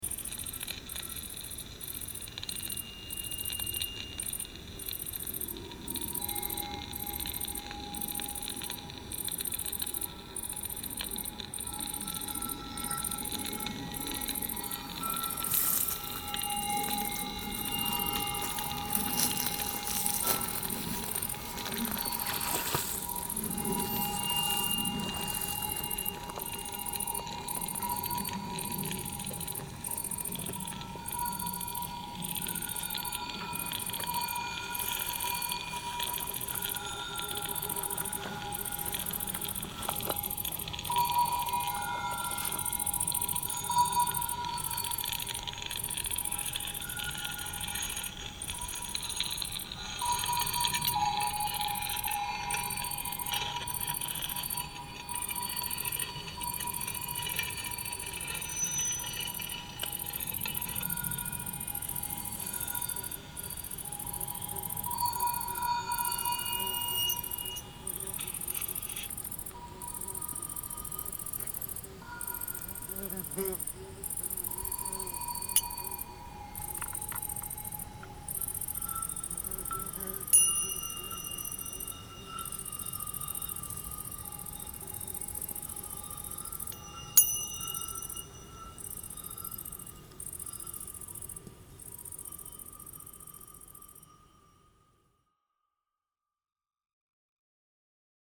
Soundscape Series